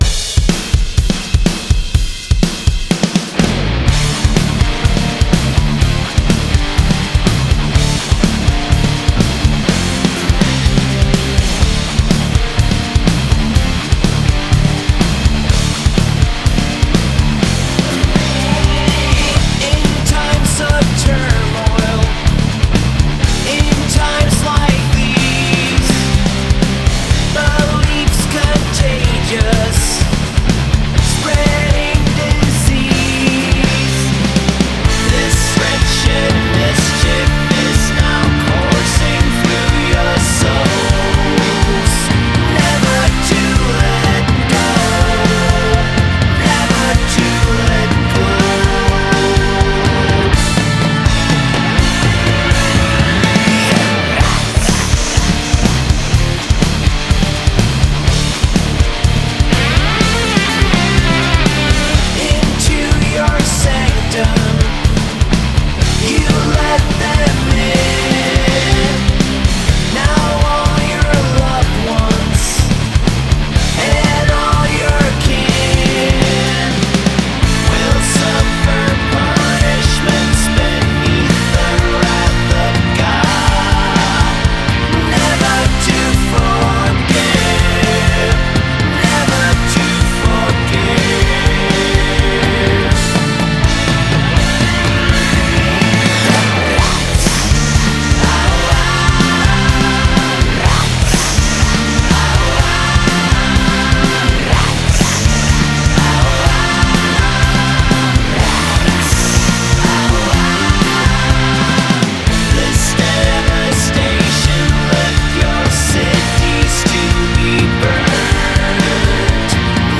Metal